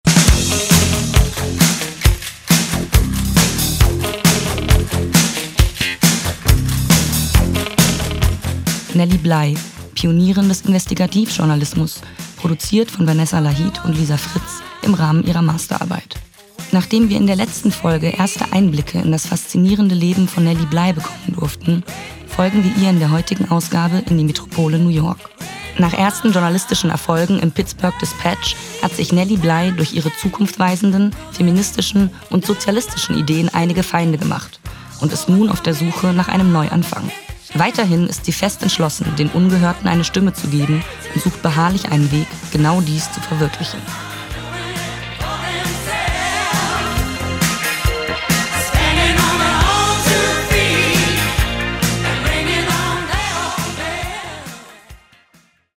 Beitrag
Die Beteiligten und ihre Rollen: